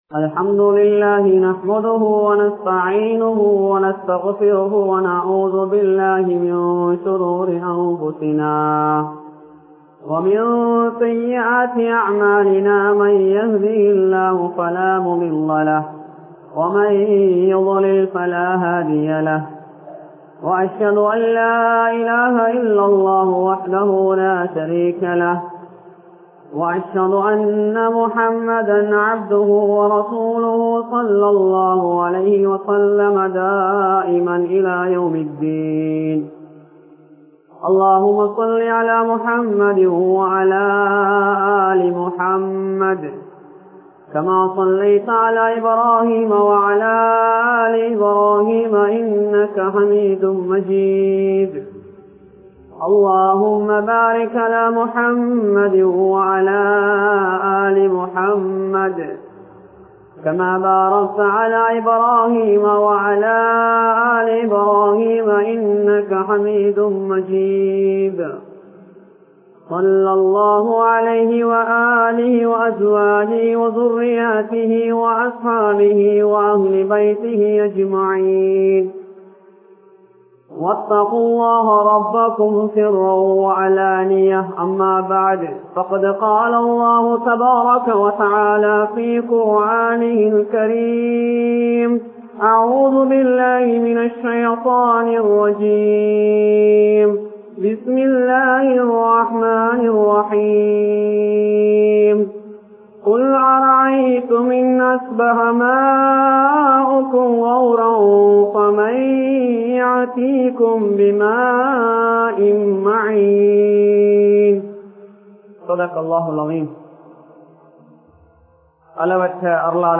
Allah vin Arulaana Thanneer (அல்லாஹ்வின் அருளான தண்ணீர்) | Audio Bayans | All Ceylon Muslim Youth Community | Addalaichenai
Arakyala Jumua Masjidh